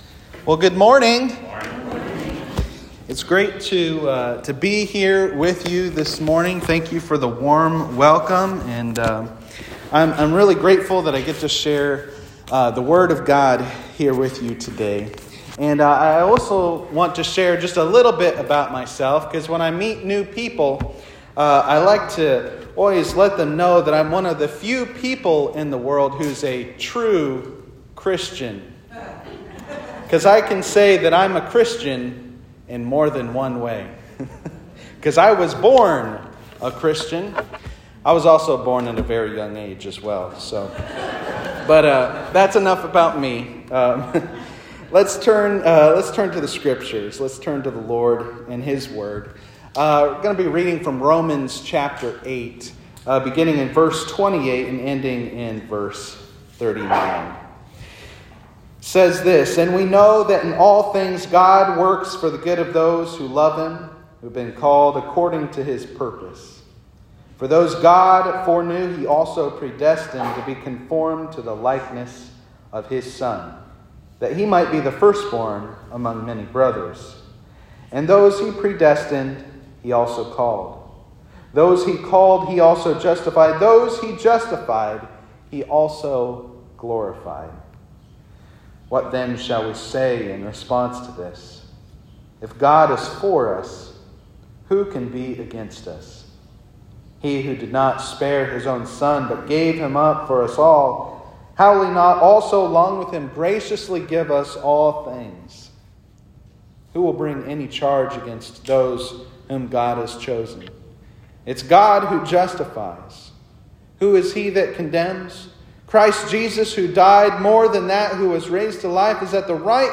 This sermon was preached at Christ United Methodist Church in East Moline, IL on Sunday, July 16, 2023.